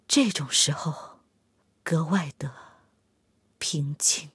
SU-122A被击毁语音.OGG